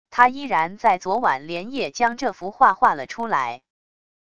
他依然在昨晚连夜将这幅画画了出来wav音频生成系统WAV Audio Player